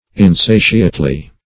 insatiately - definition of insatiately - synonyms, pronunciation, spelling from Free Dictionary Search Result for " insatiately" : The Collaborative International Dictionary of English v.0.48: Insatiately \In*sa"ti*ate*ly\, adv.